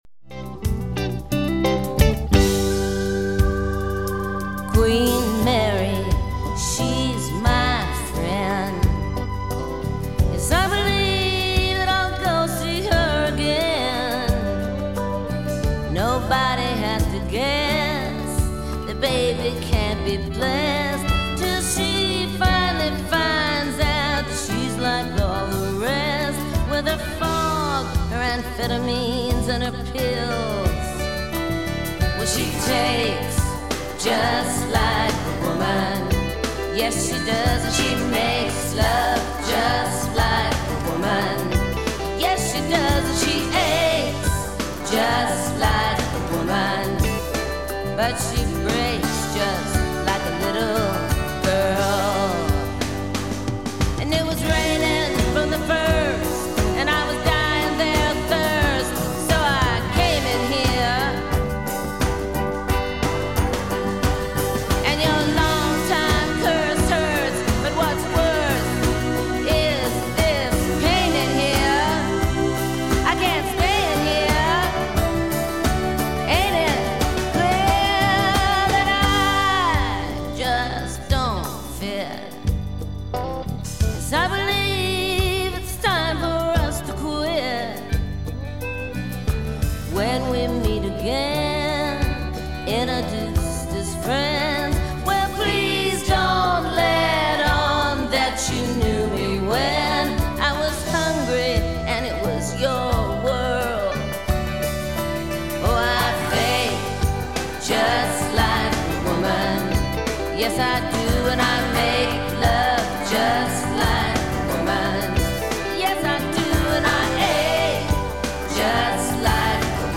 harmonica and guitar